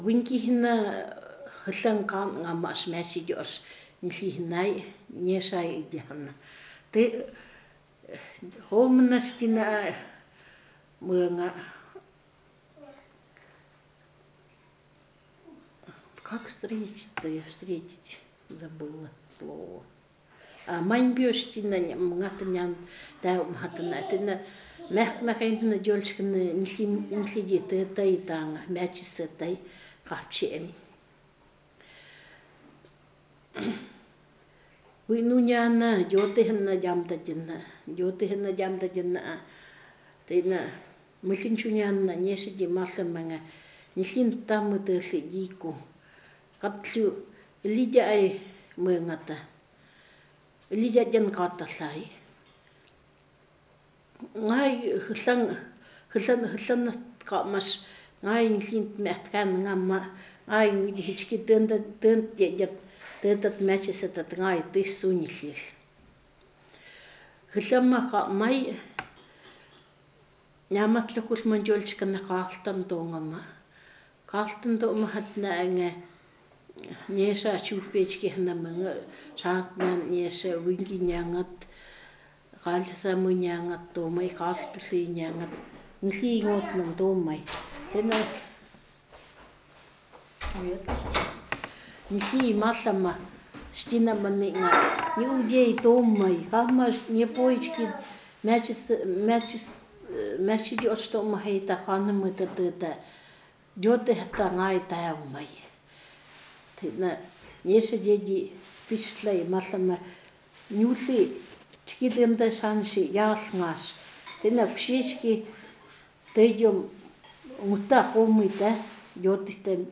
Место записи: г. Тарко-Сале